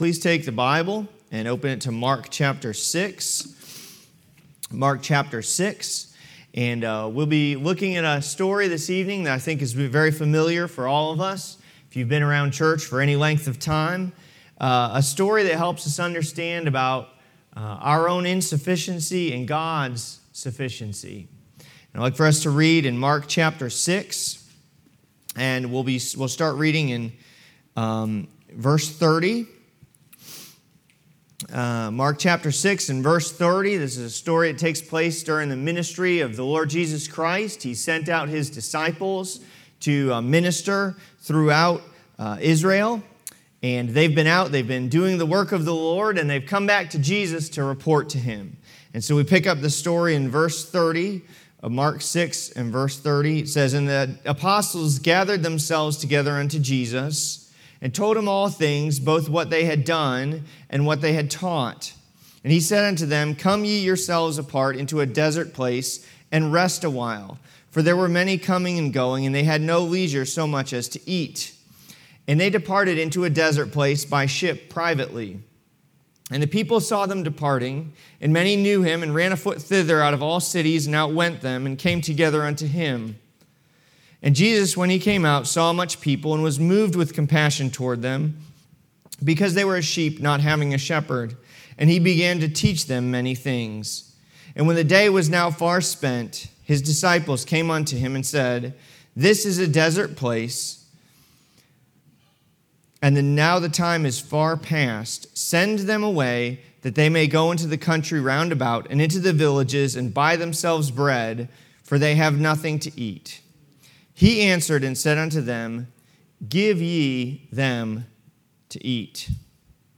Mission Conference 2025 &middot